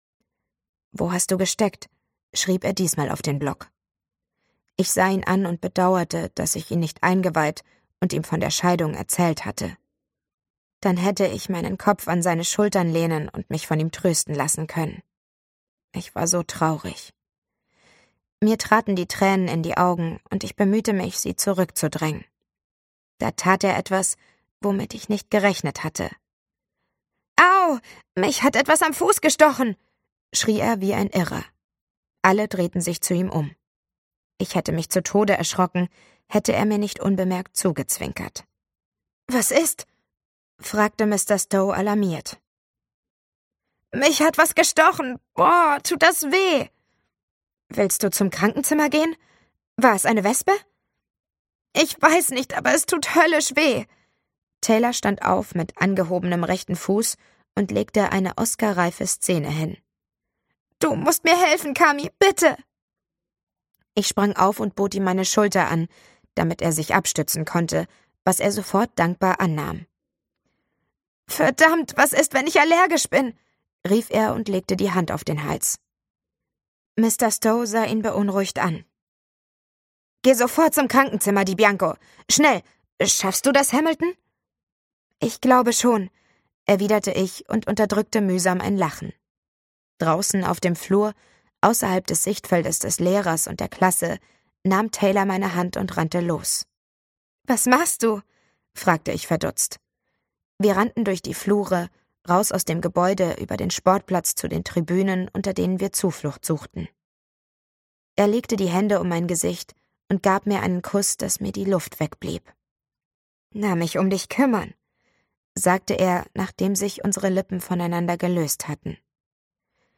NEU: "Tell Me in Secret" von Mercedes Ron - Hörbuch Download - Band 2